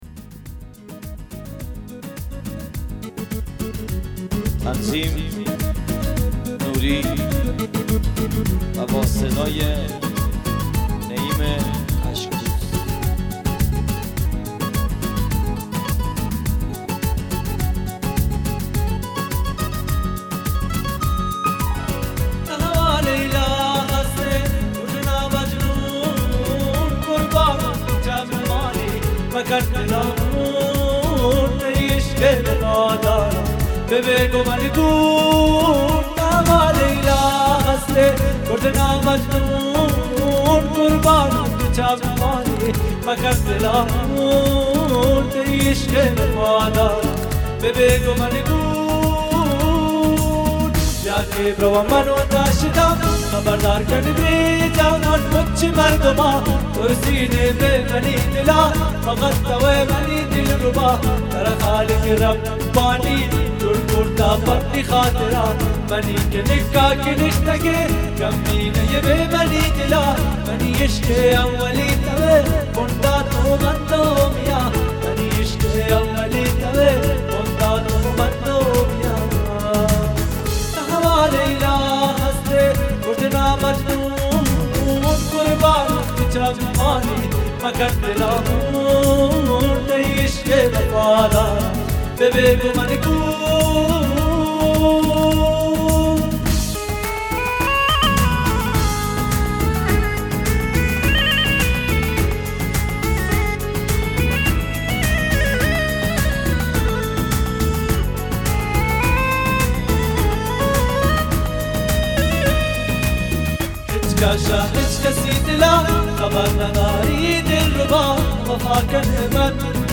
بلوچی